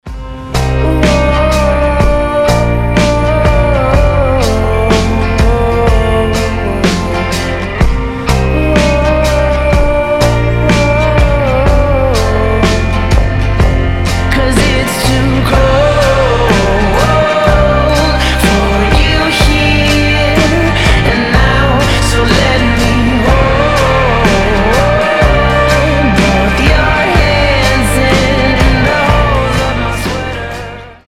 гитара
мужской голос
лирика
спокойные
медленные
indie rock